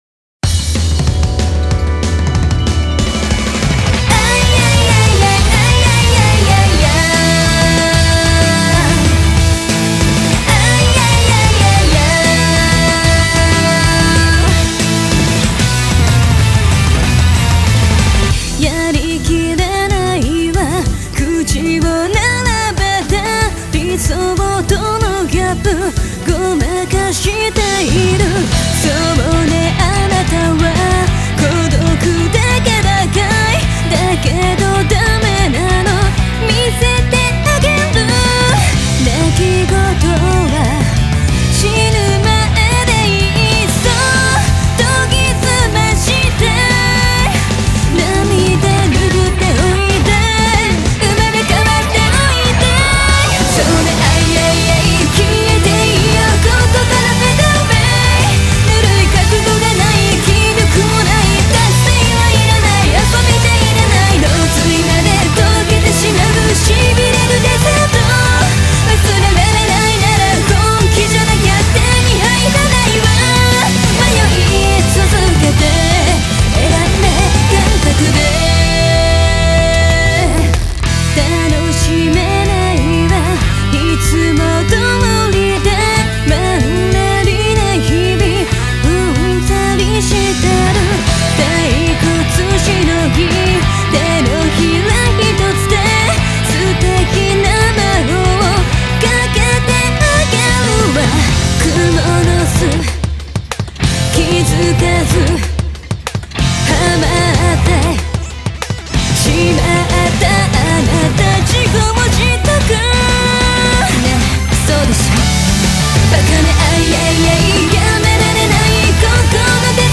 Country: Japan, Genre: J-Pop